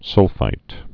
(sŭlfīt)